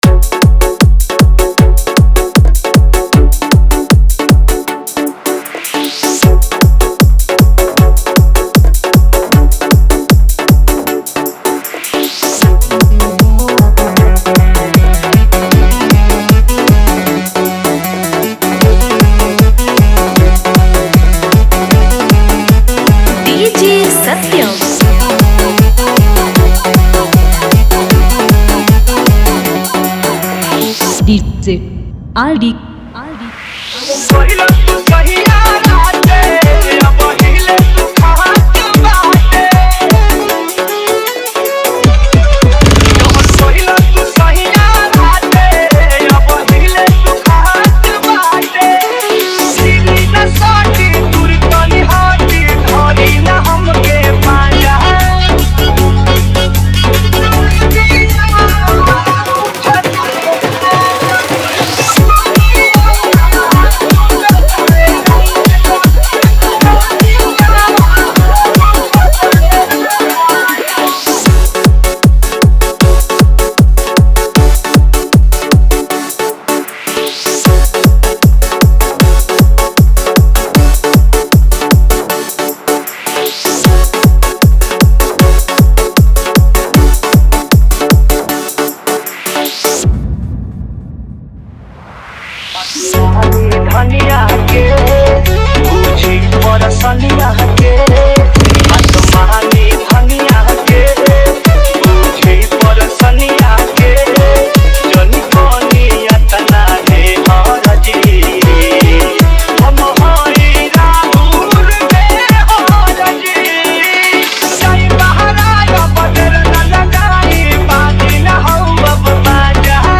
Category : Bhojpuri DJ Remix Songs